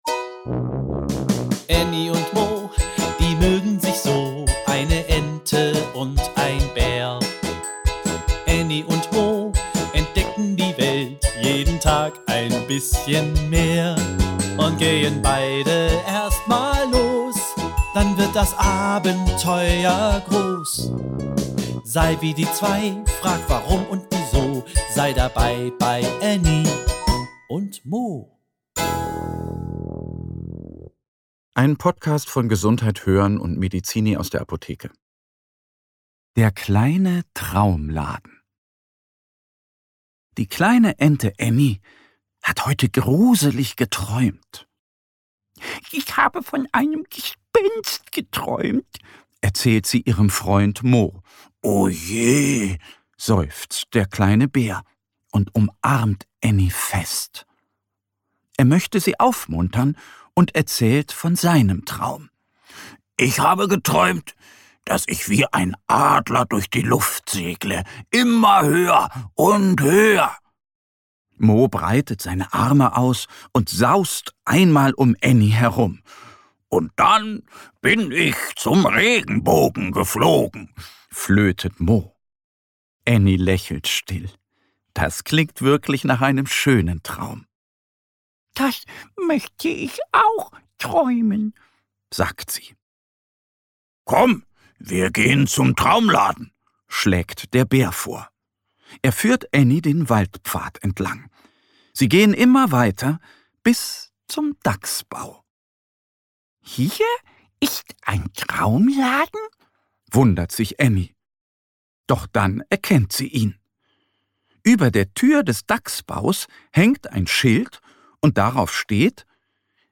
Genres: Kids & Family, Stories for Kids